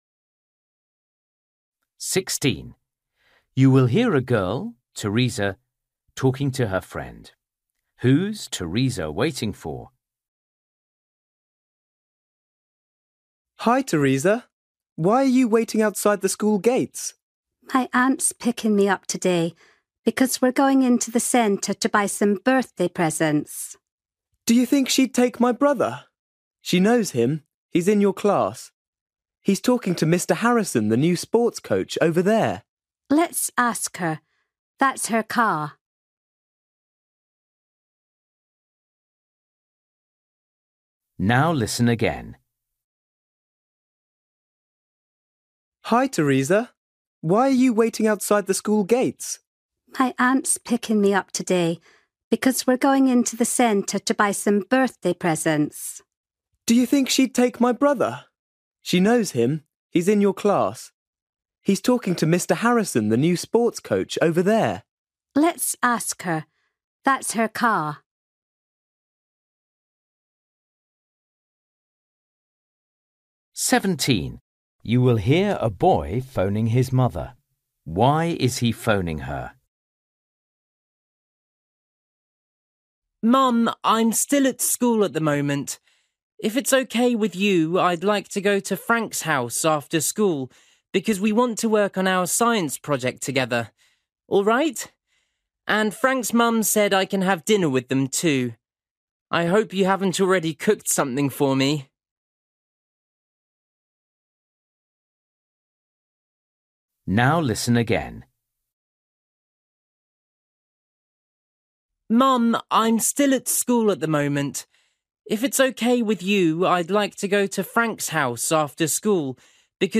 Listening: everyday short conversations
các cuộc hội thoại ngắn hàng ngày
17   You will hear a boy phoning his mother. Why is he phoning her?
18   You will hear two friends talking about a new café. How will they find out where the new café is?
19   You hear a girl and her dad talking about a boat tour. Why do they decide to go on the boat tour today?